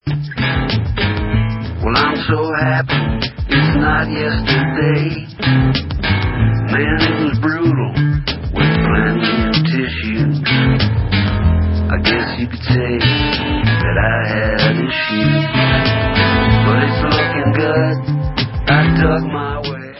indie-rocková kapela